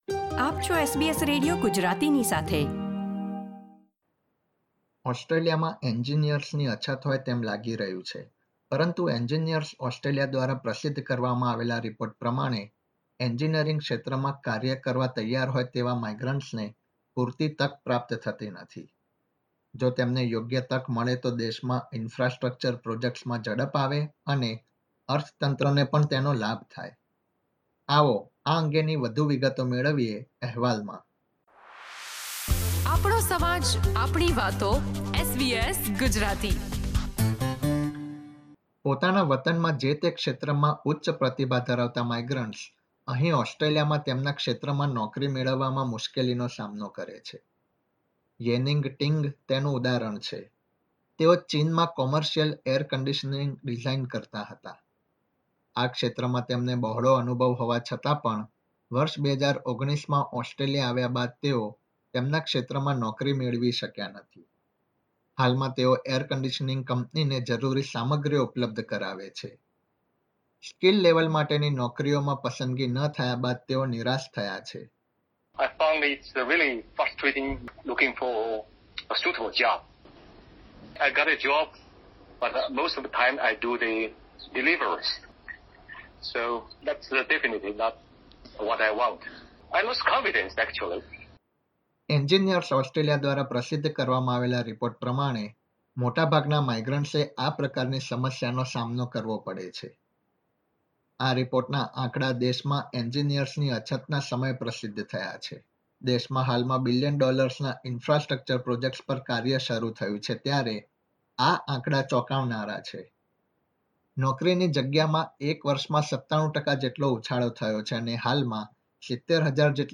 ઓસ્ટ્રેલિયામાં એન્જીનિયર્સની અછત હોય તેવી પરિસ્થિતિ ઉભી થઇ છે પરંતુ, એન્જીનિયર્સ ઓસ્ટ્રેલિયા દ્વારા પ્રસિદ્ધ કરવામાં આવેલા આંકડા પ્રમાણે, ઓસ્ટ્રેલિયામાં માઇગ્રન્ટ્સ એન્જીનિયર્સને નોકરીની યોગ્ય તક પ્રાપ્ત થતી નથી. વિગતો મેળવીએ અહેવલામાં.